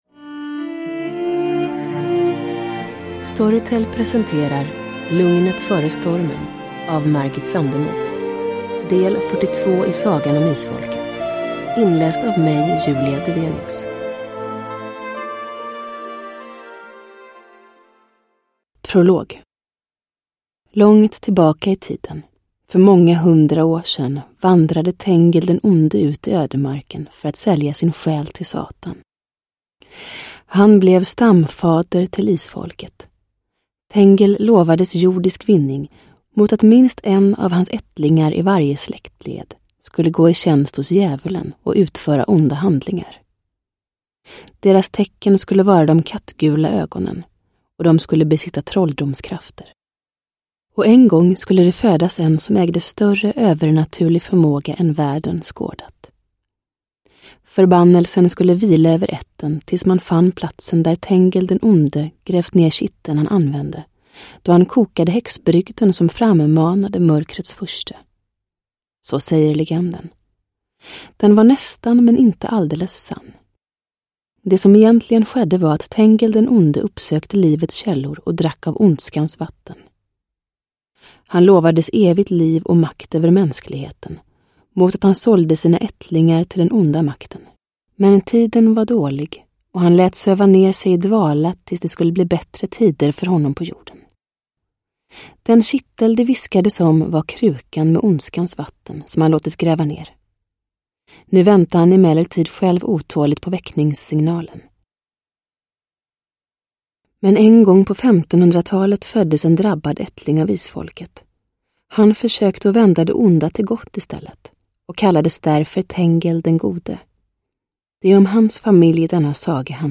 Sagan om Isfolket, nu äntligen som ljudbok.
Uppläsare: Julia Dufvenius